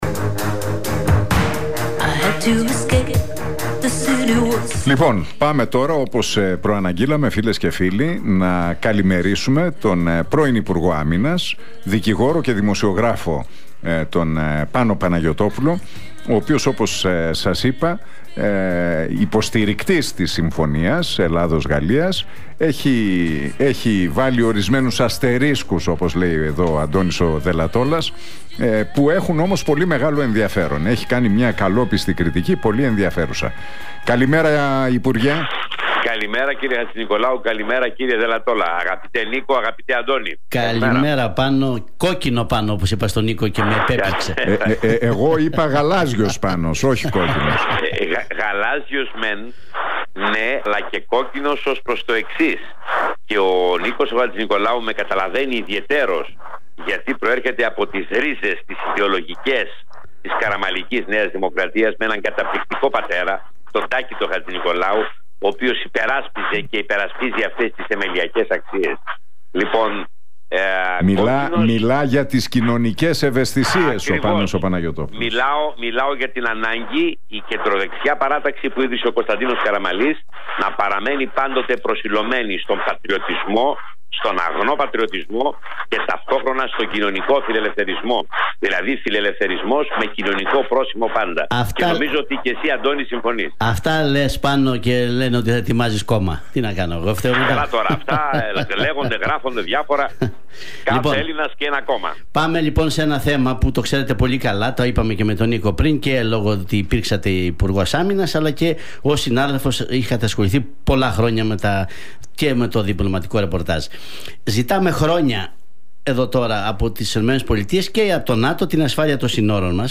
Ο πρώην υπουργός Άμυνας, δικηγόρος και δημοσιογράφος, Πάνος Παναγιωτόπουλος, σχολίασε τη συμφωνία με τη Γαλλία σε συνέντευξή του στον Realfm 97,8.